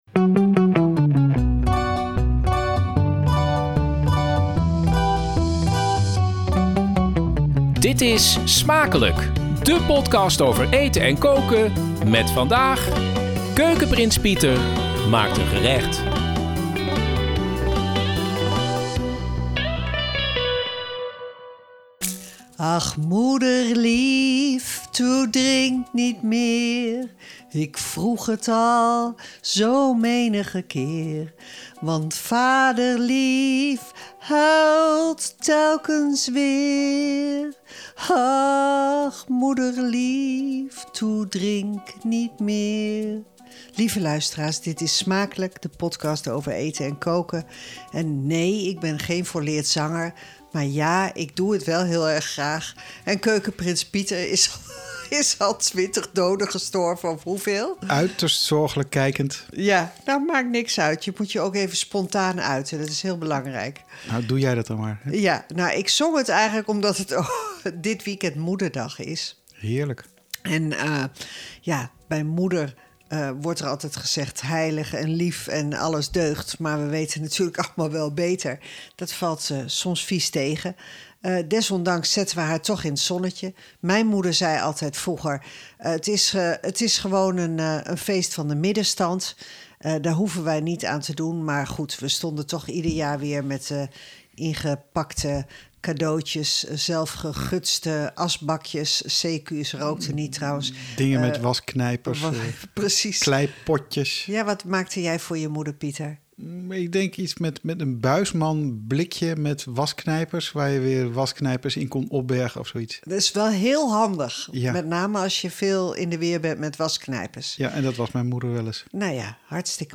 We zitten op een andere locatie en er is publiek, gezellig!